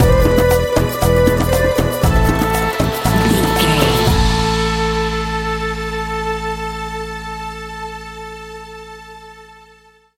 Aeolian/Minor
World Music
percussion
congas
bongos
djembe